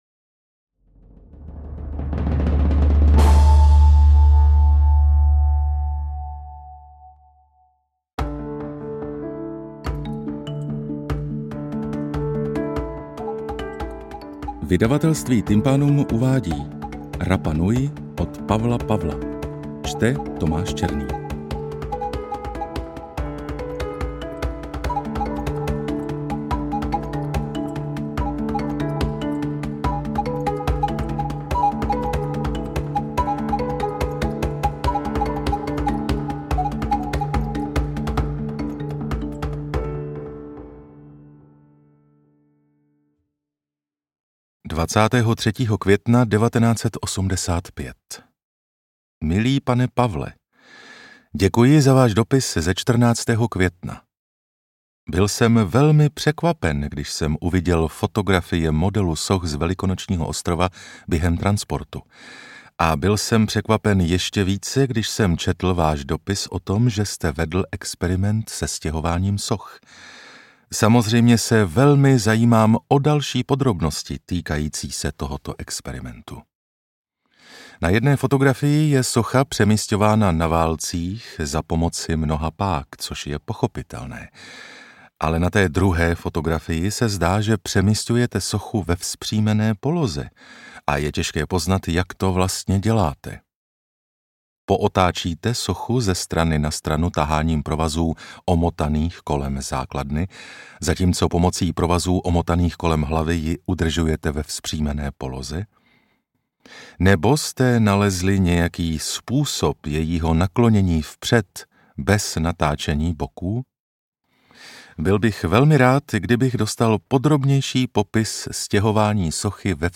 AudioKniha ke stažení, 36 x mp3, délka 10 hod. 25 min., velikost 576,4 MB, česky